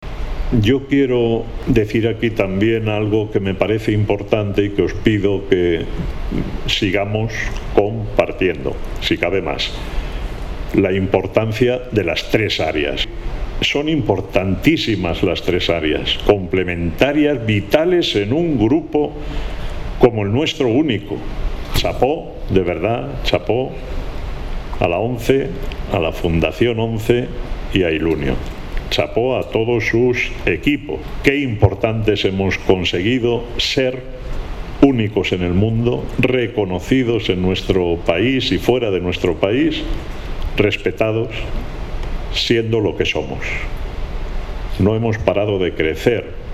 dijo formato MP3 audio(1,09 MB)Lo hizo durante la clausura del último Comité de Coordinación General (CCG) ordinario, celebrado presencial y telemáticamente el  9 y 10 de junio en Madrid, que reunió a más de 200 personas, responsables de todos los equipos de gestión de toda España.